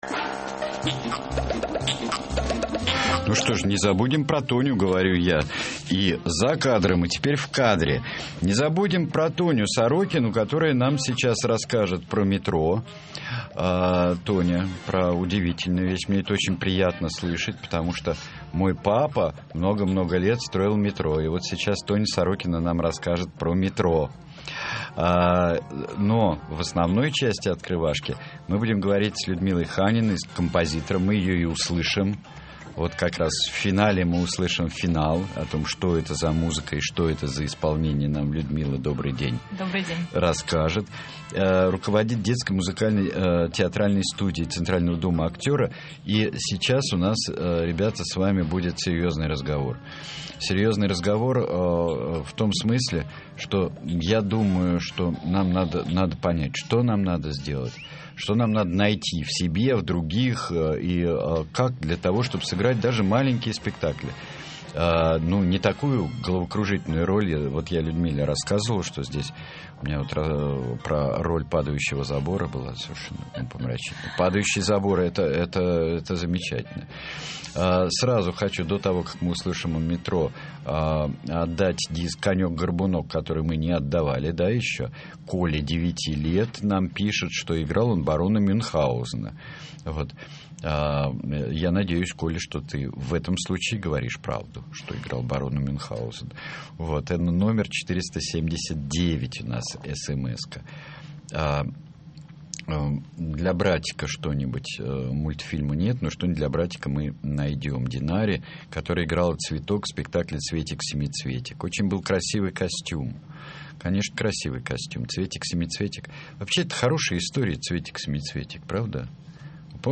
В эфире радиостанции «Эхо Москвы»